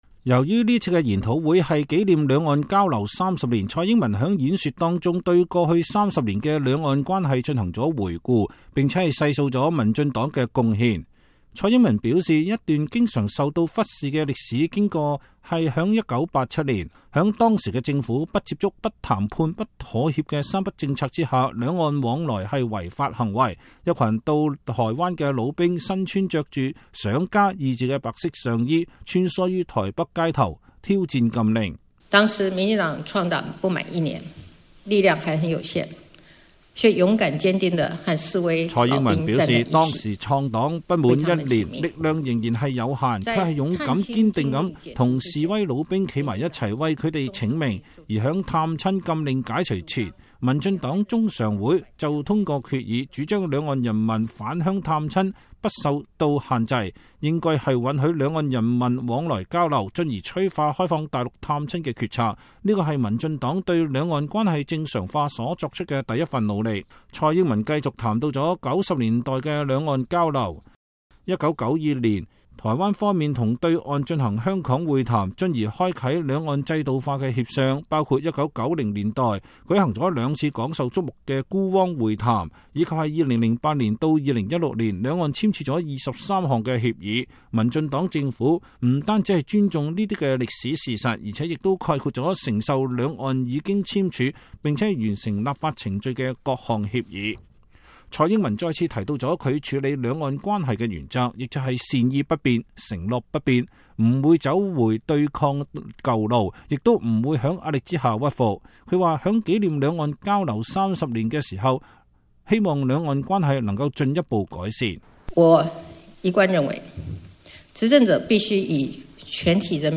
台灣總統蔡英文10月26日出席陸委會舉辦的“兩岸交流30年回顧與前瞻研討會”，這是在中共十九大後蔡英文首次公開就兩岸關係發表講話，發言內容備受關注。